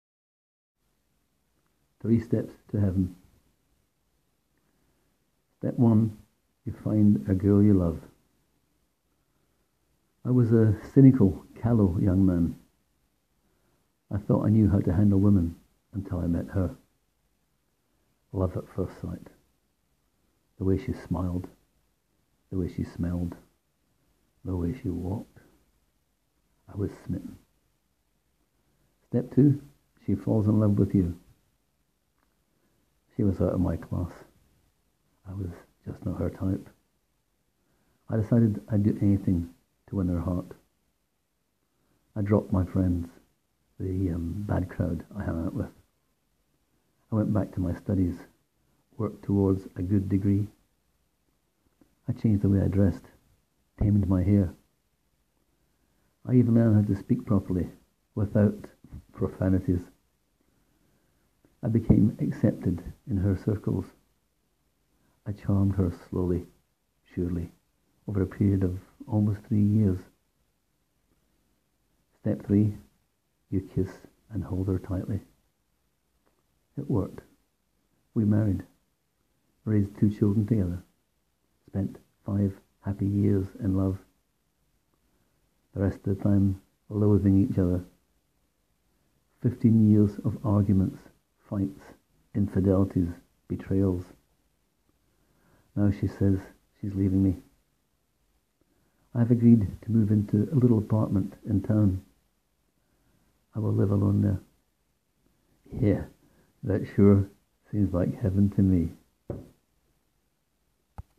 Click here to hear me read this 2 minute tale: